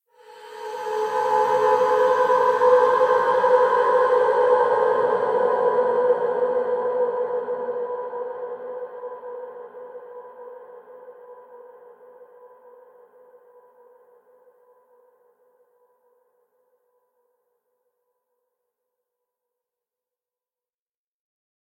Напряженная атмосфера денского призрака
napryazhennaya_atmosfera_denskogo_prizraka_ofo.mp3